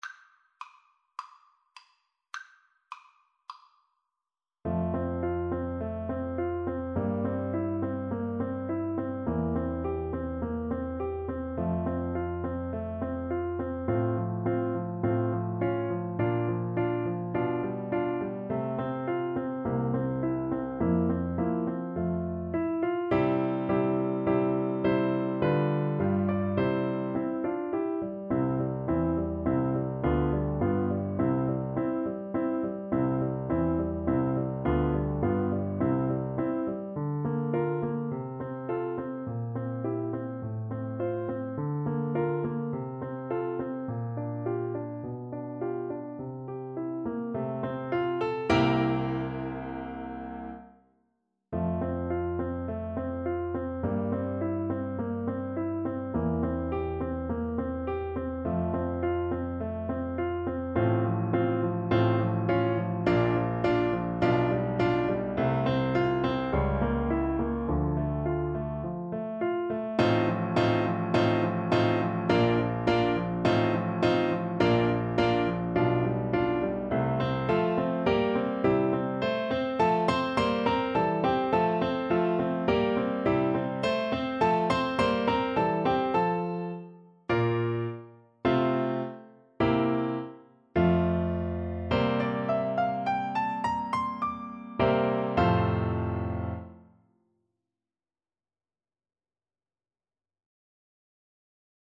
Flute
2/4 (View more 2/4 Music)
F major (Sounding Pitch) (View more F major Music for Flute )
= 52 Andante
Classical (View more Classical Flute Music)